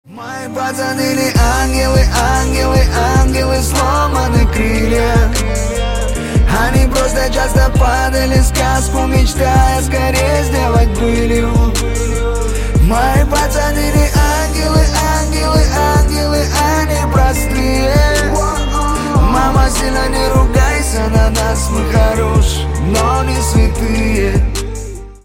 Рэп Хип-Хоп